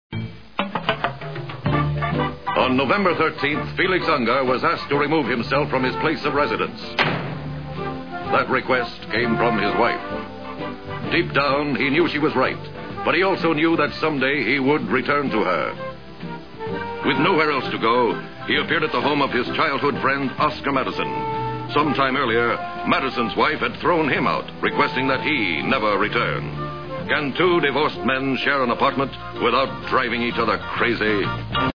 ABC Situation Comedy - 114 Episodes
Narrator:    William Woodson